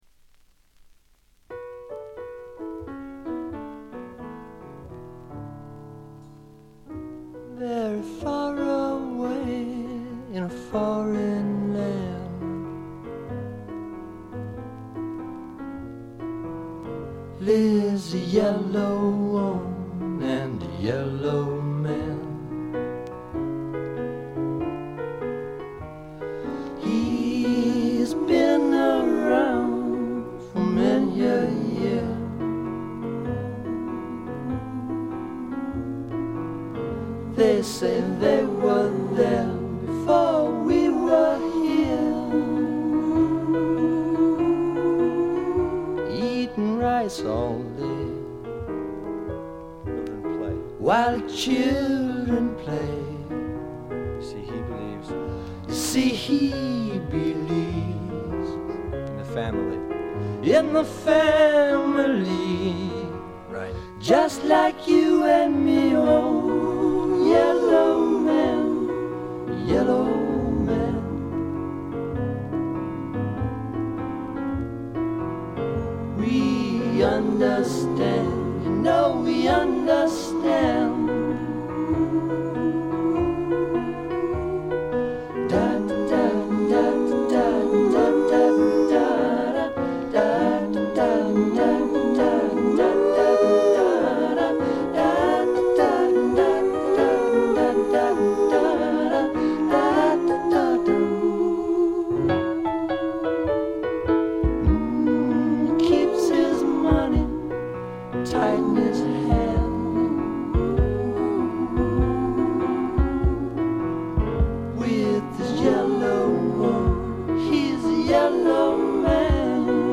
軽微なバックグラウンドノイズ程度。
試聴曲は現品からの取り込み音源です。